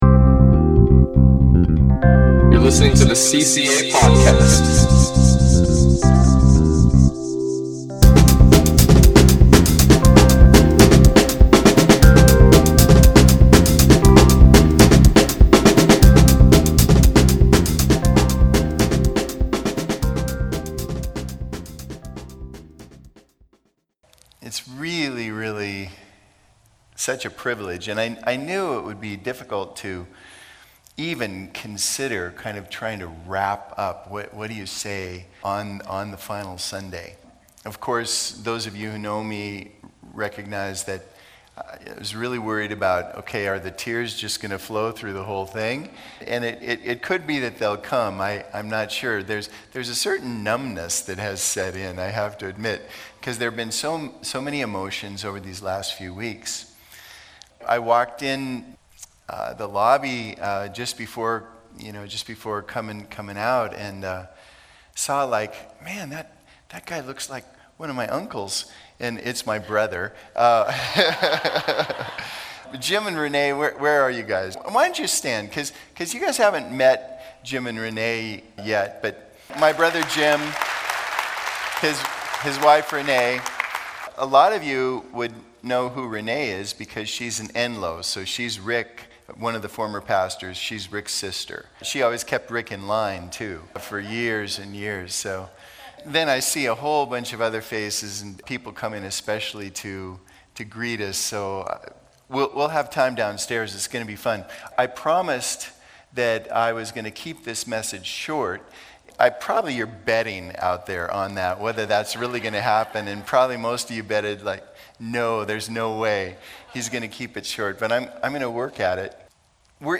Listen to Message | Download Notes